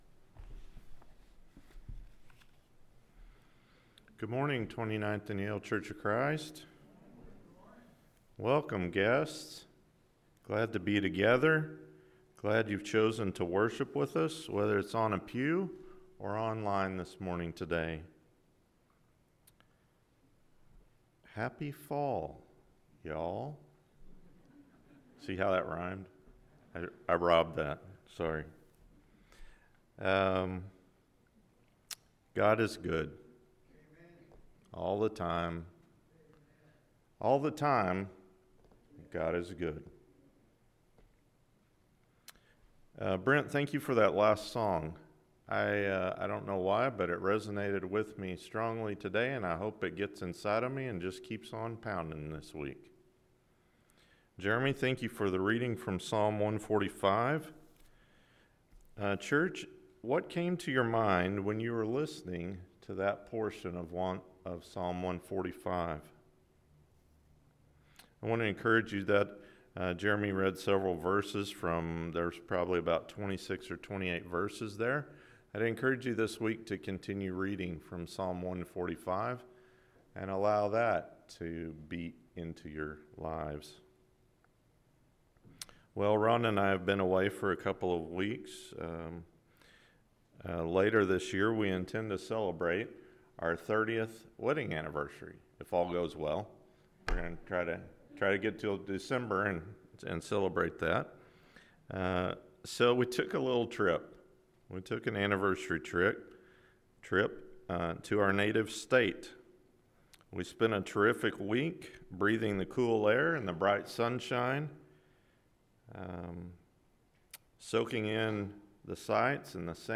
Kingdom Stories: The Growing Seed & The Mustard Seed Sermon – Mark 4:26-34 – Sermon
KingdomStoriesTheGrowingSeedAndTheMustardSeedSermonAM.mp3